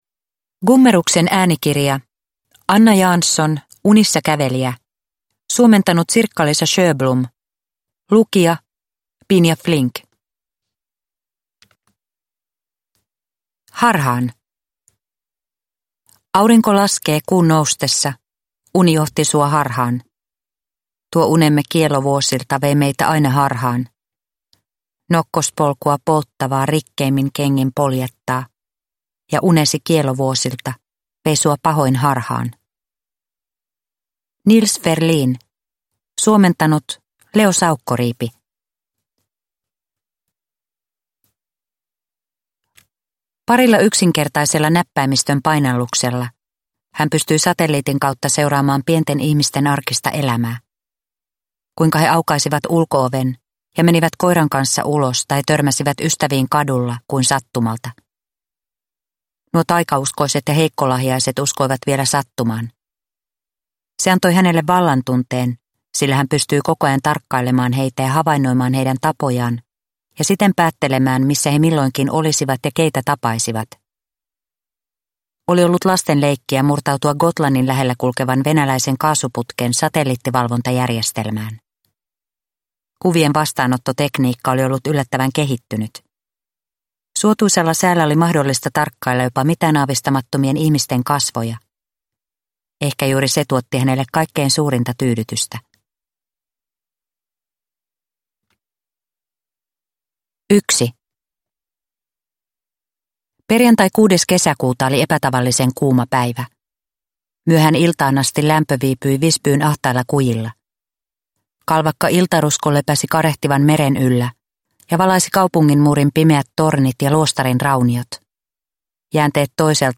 Unissakävelijä – Ljudbok – Laddas ner